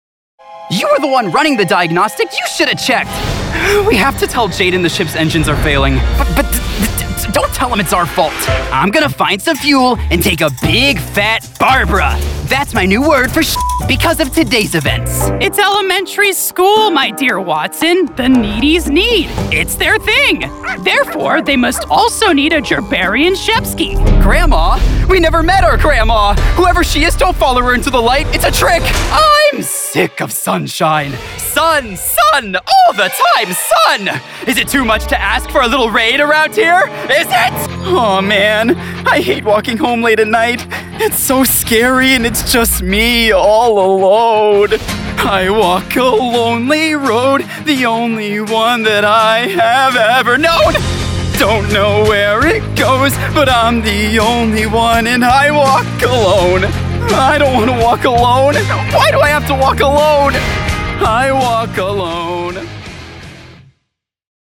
Teenager, Young Adult, Adult
midwestern us | natural
standard us | natural
ANIMATION 🎬